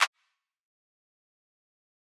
JJ_Clap2.wav